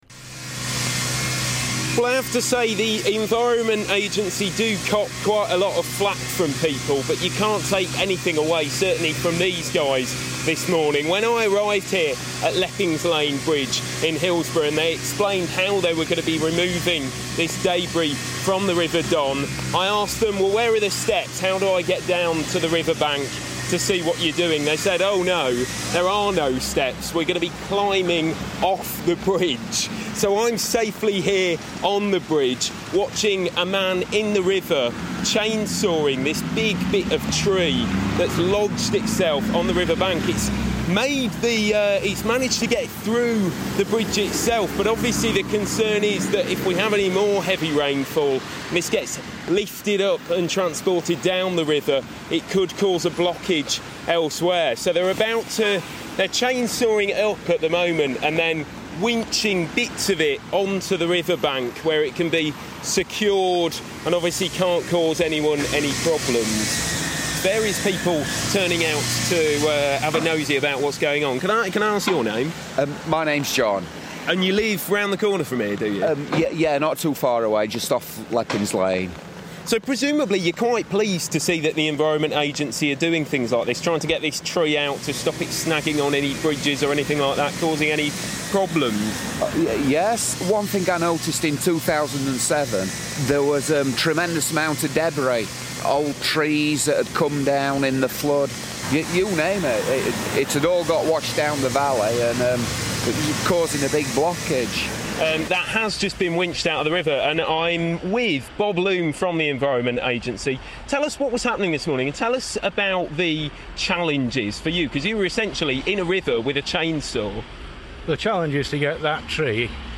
Following the bad weather, the Environment Agency have been out in force in South Yorkshire, removing debris from watercourses. Radio Sheffield spent the morning with them.